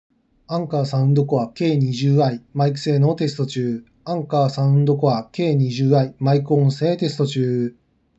マイク性能〇
✅「Anker Soundcore K20i」マイク性能
少しこもり気味だけど1万円以下の平均ぐらいの性能はある。